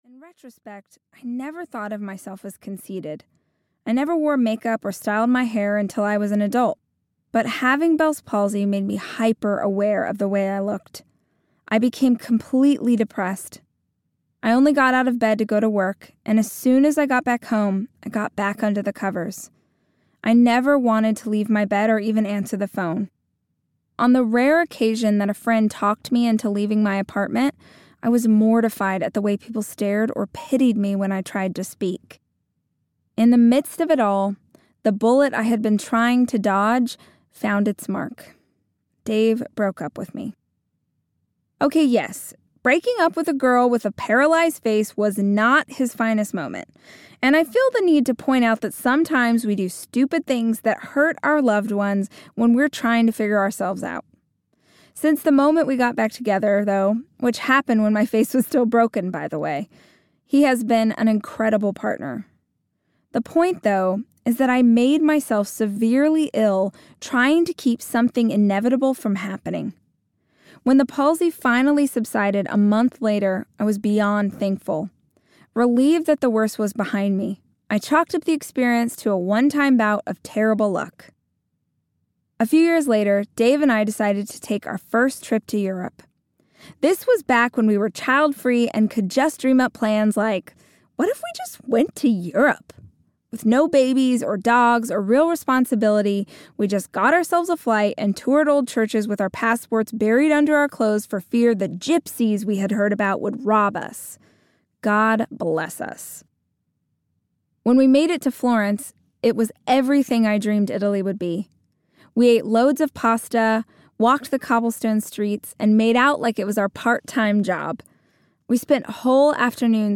Girl, Wash Your Face Audiobook
7.08 Hrs. – Unabridged